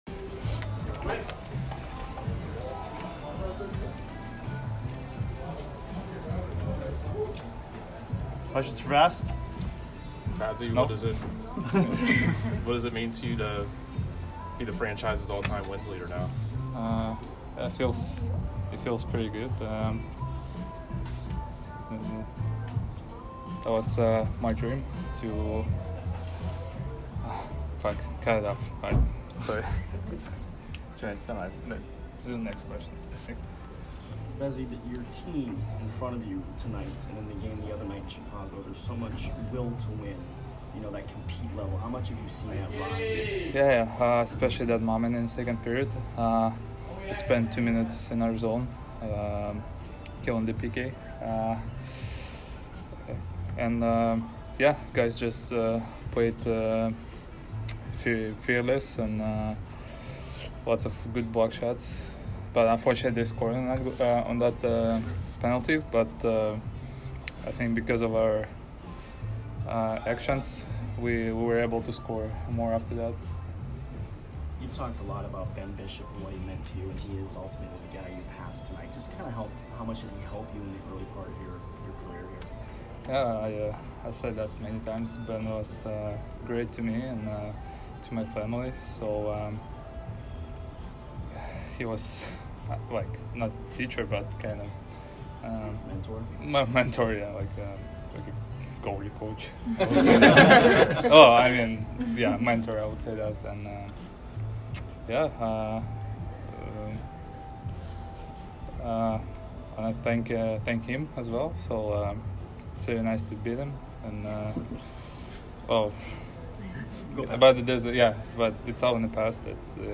Andrei Vasilevskiy post-game 11/23